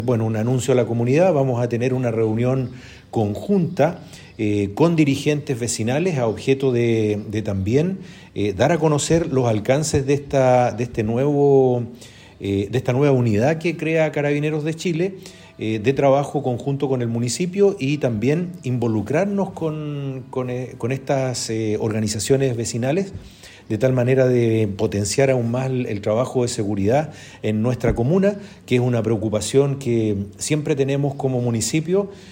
Cuna-Armando-Flores-Jimenez.mp3